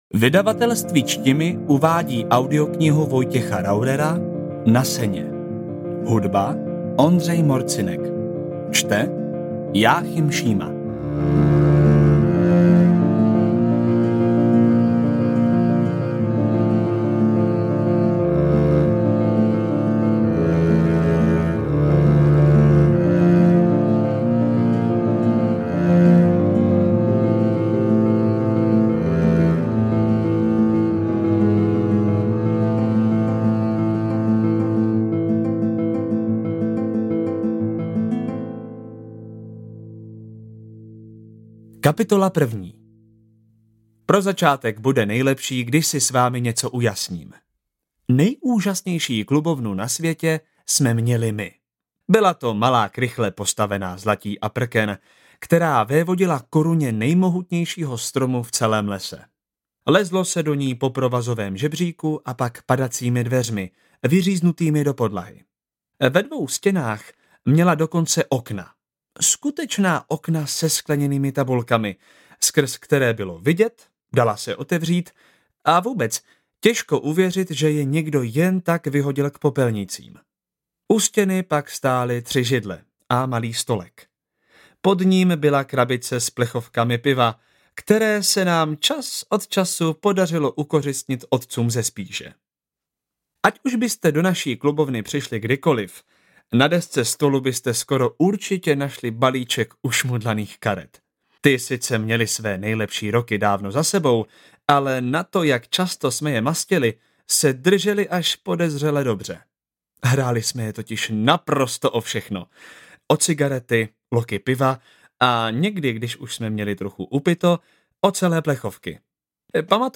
Kategorie: Román